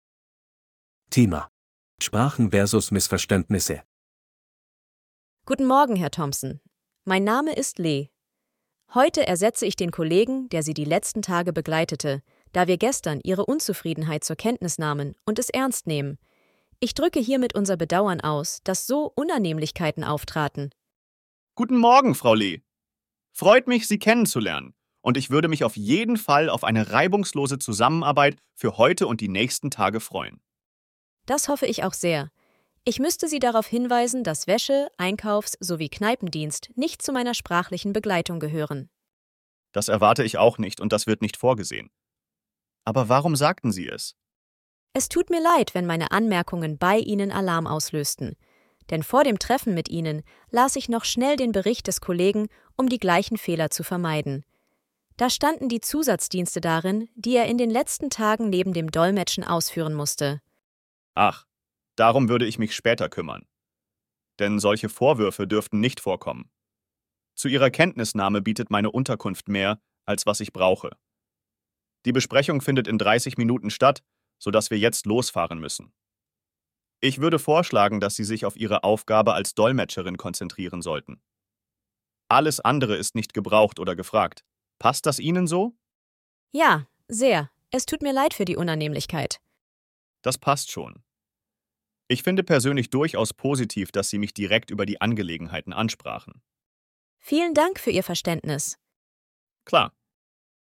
Audio text for the dialogs in task 4: